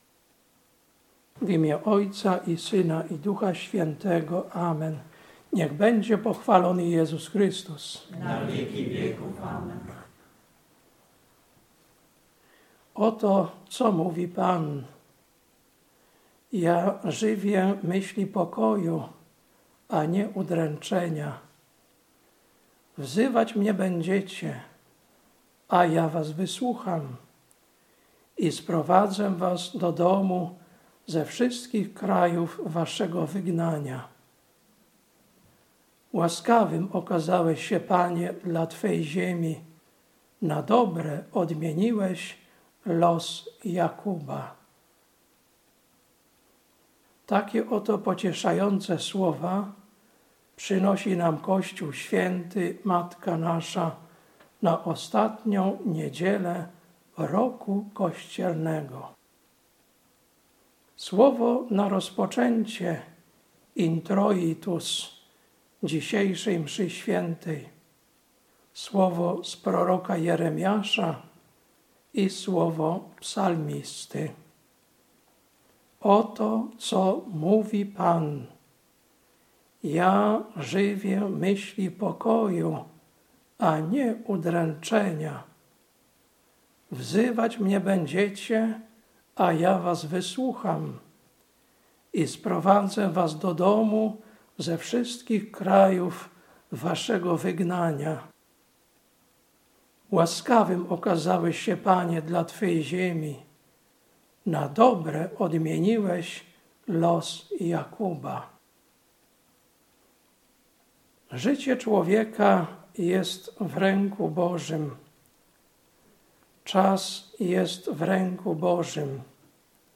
Kazanie na XXIV i Ostatnią Niedzielę po Zesłaniu Ducha Świętego, 23.11.2025 Lekcja: Kol 1, 9-14 Ewangelia: Mt 24, 15–35